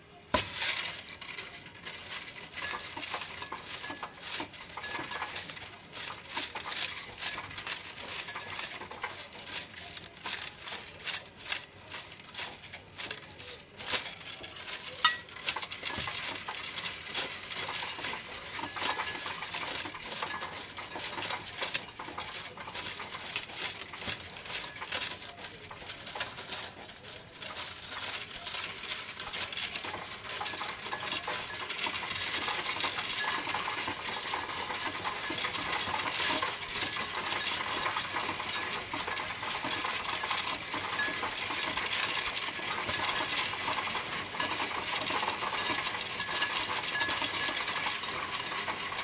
超市 " 投币机填充超市
描述：倒空硬币到超级市场硬币机器
Tag: 超市 更改 现金 硬币